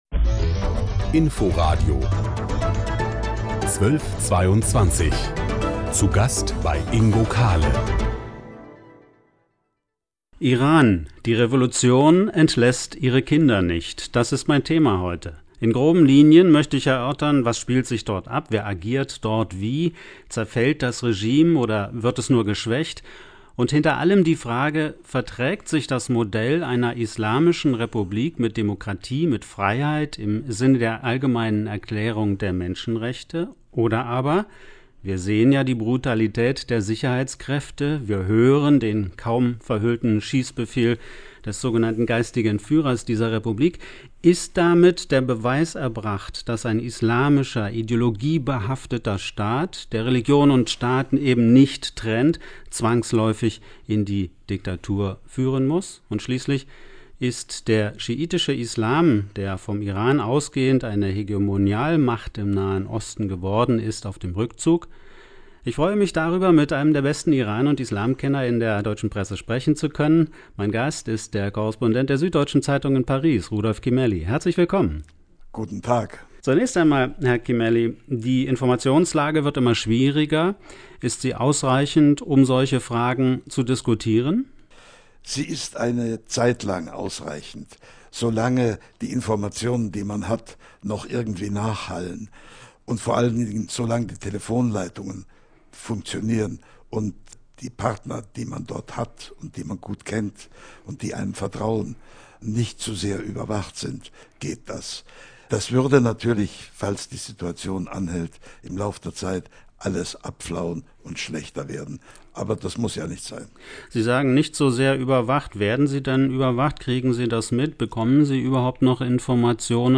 Gespräch